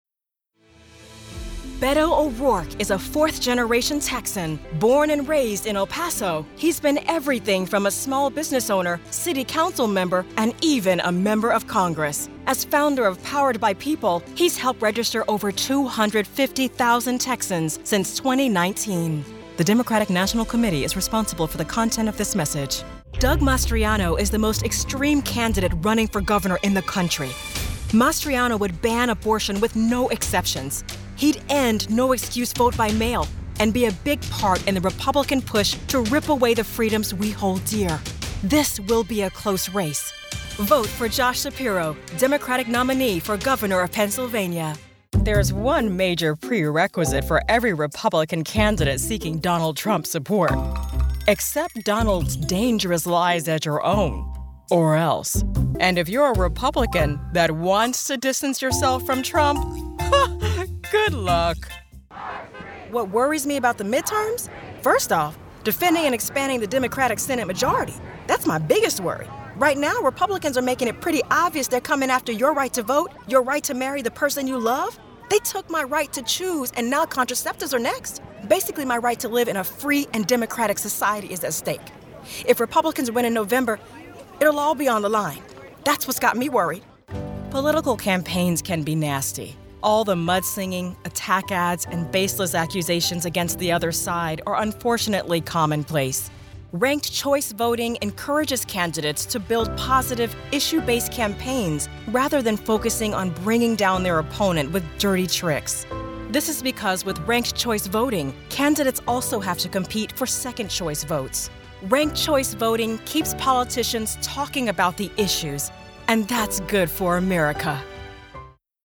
With an excellent female political voice artist talent, your candidate will rise to the top.